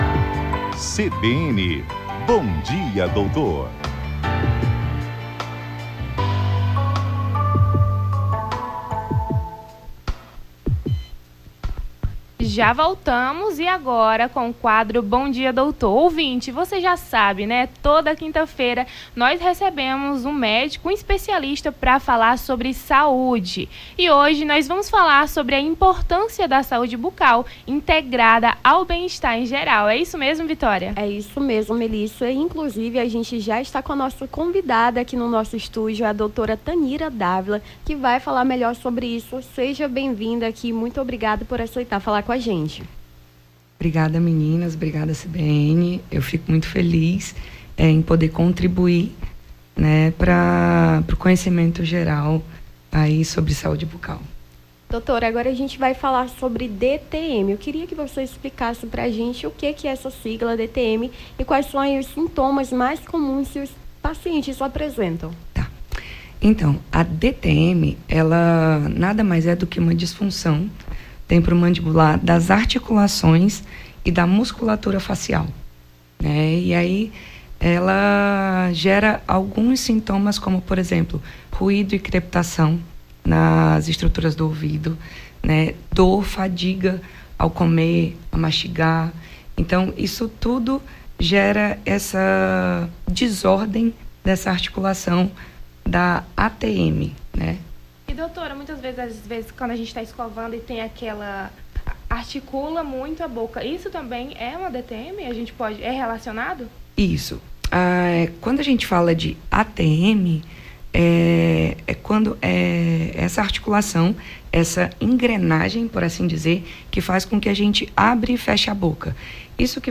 No Jornal da Manhã desta quinta-feira (01), as apresentadoras